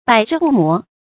百世不磨 注音： ㄅㄞˇ ㄕㄧˋ ㄅㄨˋ ㄇㄛˊ 讀音讀法： 意思解釋： 磨：消滅，磨滅。千秋萬代永不磨滅 出處典故： 南朝 宋 范曄《后漢書 南匈奴傳論》：「千里之差，興自毫端，失得之源， 百世不磨 矣。」